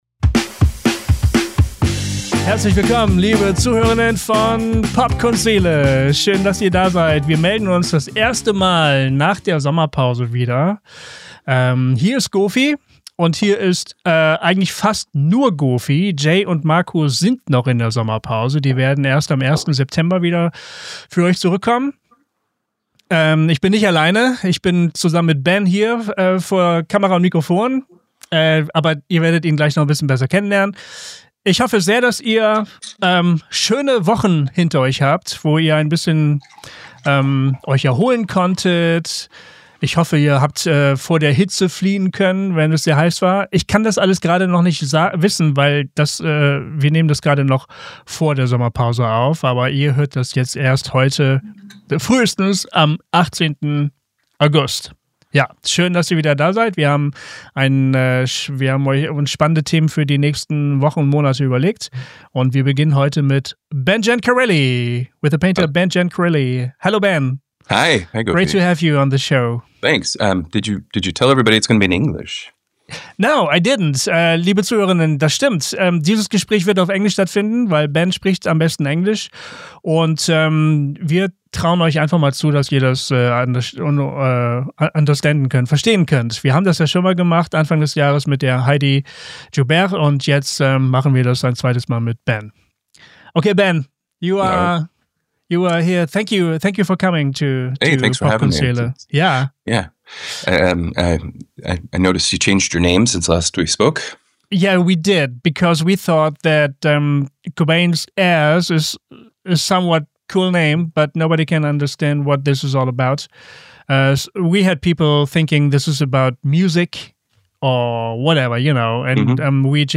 Viel Spaß bei dieser spannenden Unterhaltung auf Englisch.